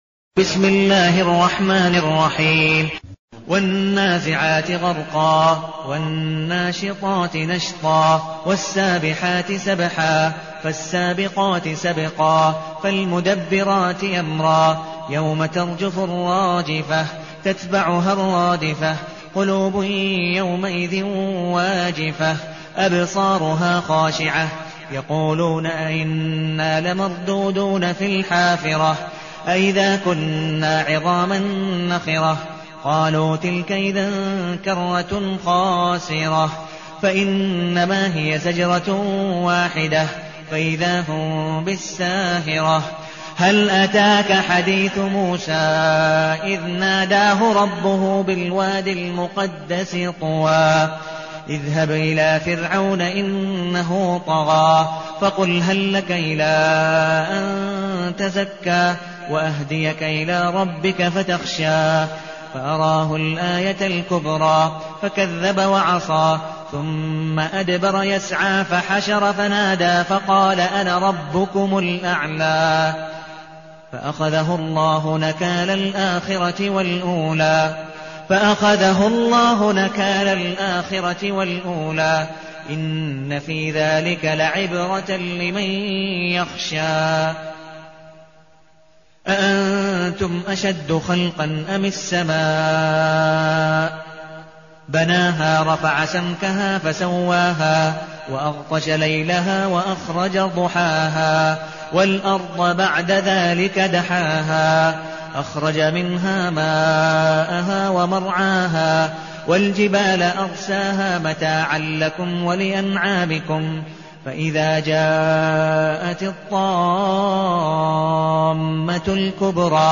المكان: المسجد النبوي الشيخ: عبدالودود بن مقبول حنيف عبدالودود بن مقبول حنيف النازعات